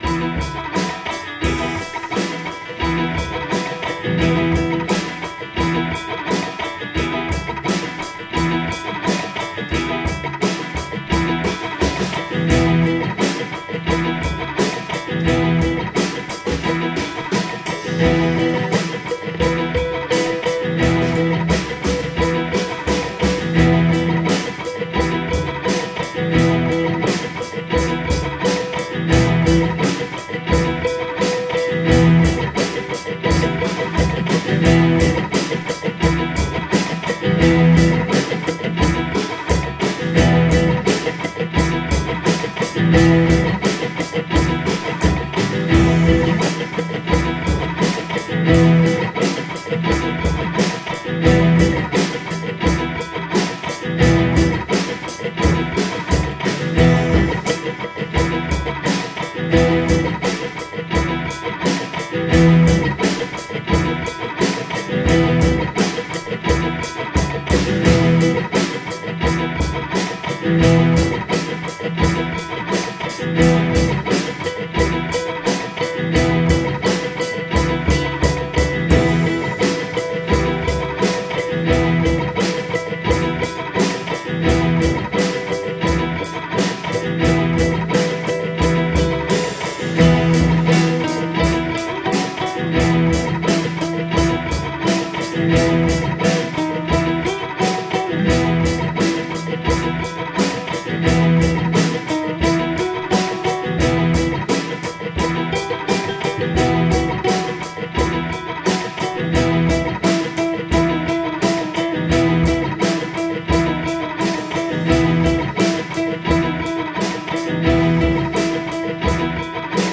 Compressed new drums
I compressed the levels so it should punch more. The end has an interesting melody that is slightly out of phase with the main rhythm.